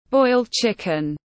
Boiled chicken /bɔɪld tʃɪk.ɪn/